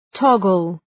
Προφορά
{‘tɒgəl}